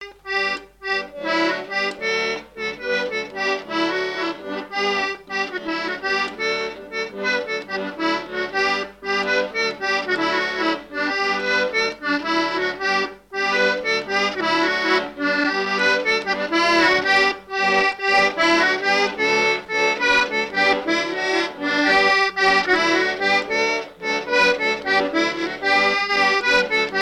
Mémoires et Patrimoines vivants - RaddO est une base de données d'archives iconographiques et sonores.
Couplets à danser
branle : courante, maraîchine
Pièce musicale inédite